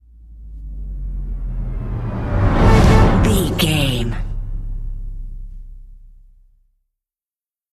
Epic / Action
Fast paced
In-crescendo
Aeolian/Minor
A♭
Fast
synth effects
driving drum beat